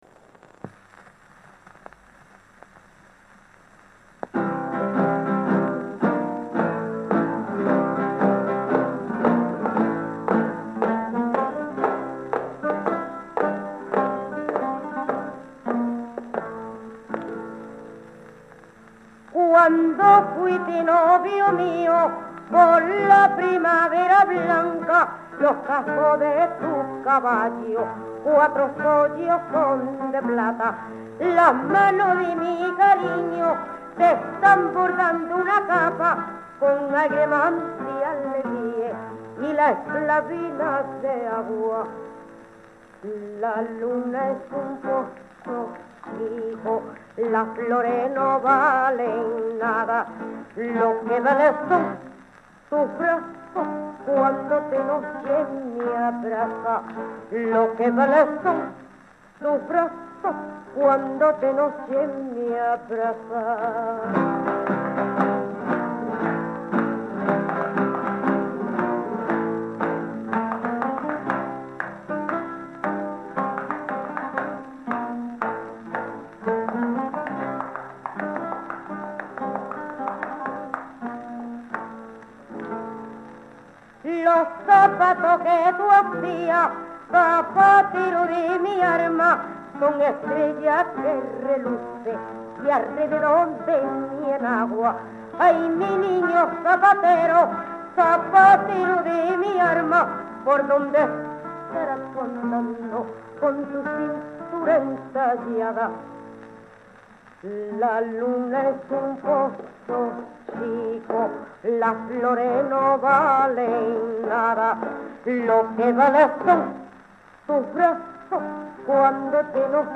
These two sides were probably recorded around 1950 .